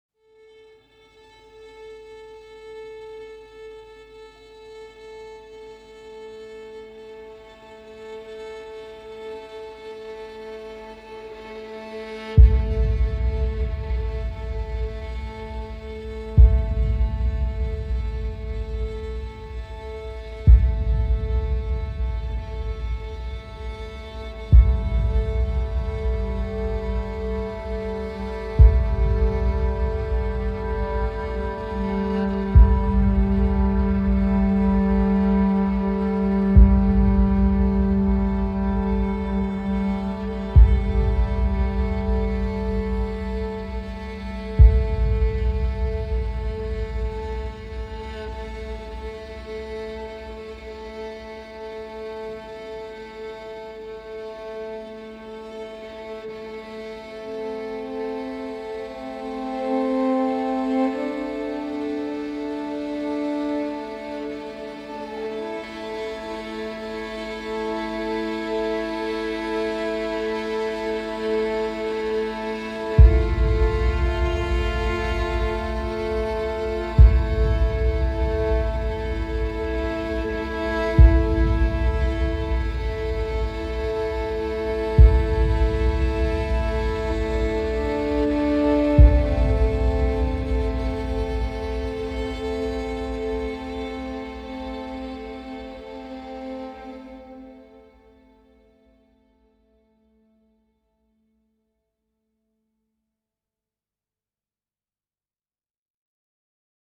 Dark Drone Version